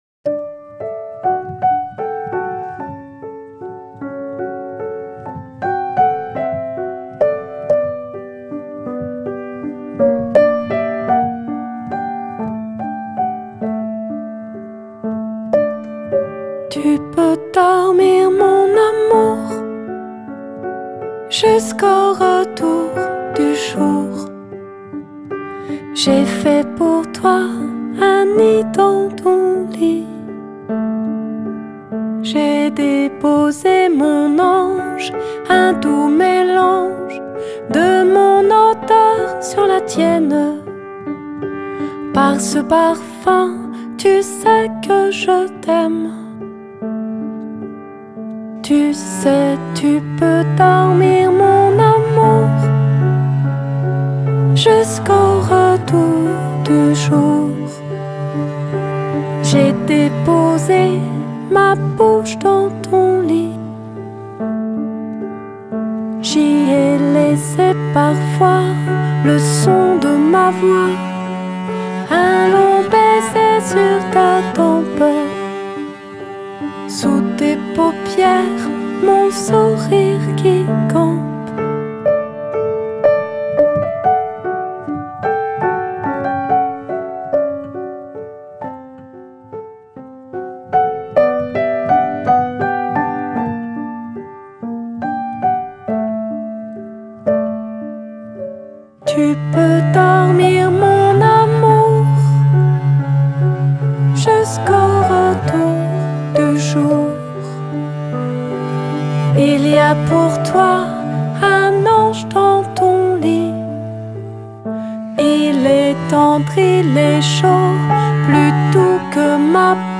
berceuses contemporaines et poétiques
douces, moelleuses, aériennes, délicates.